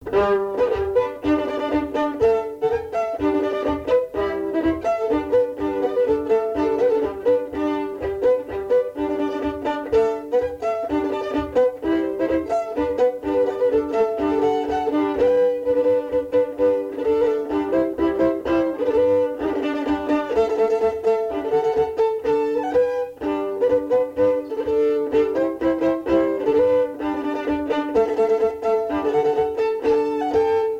Mémoires et Patrimoines vivants - RaddO est une base de données d'archives iconographiques et sonores.
danse : polka
Pièce musicale inédite